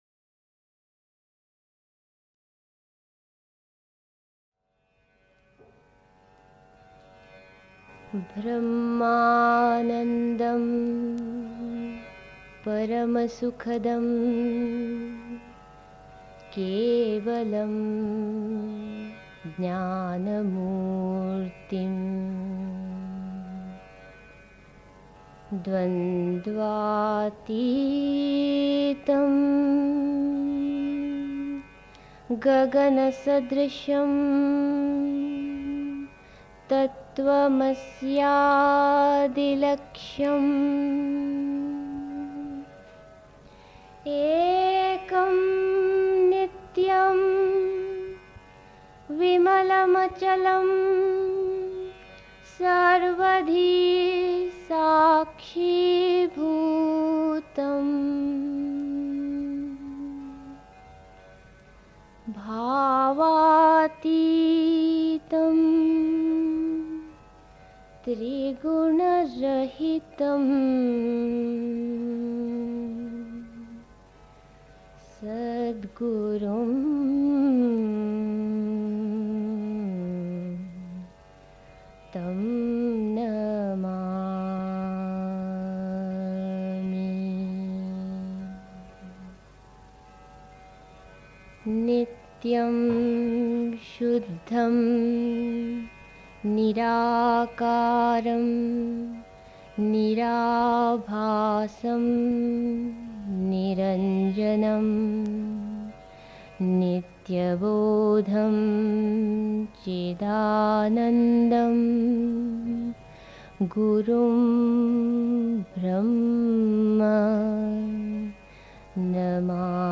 1. Einstimmung mit Musik. 2.
Das erste Wort des supramentalen Yoga (Sri Aurobindo, CWSA, Vol. 32, p. 36) 3. Zwölf Minuten Stille.